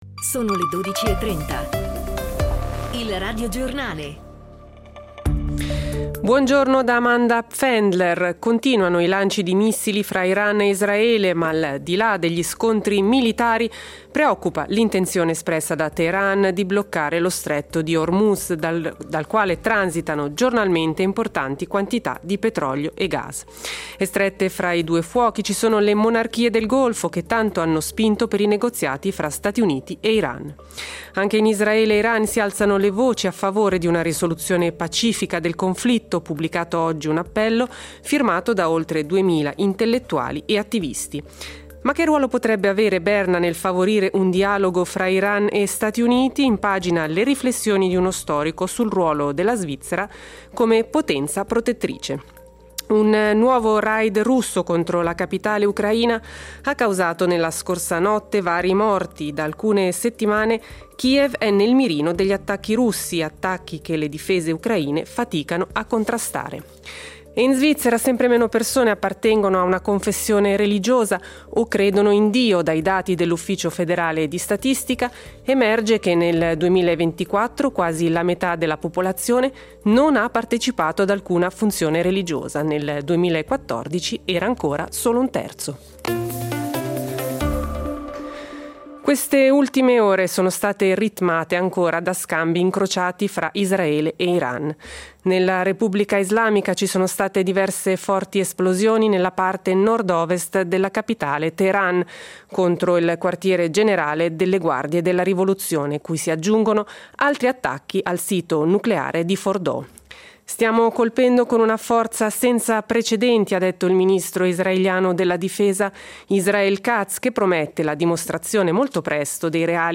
I fatti del giorno, dalla Svizzera e del mondo. Il Radiogiornale punteggia le 24 ore dell'offerta radiofonica della RSI.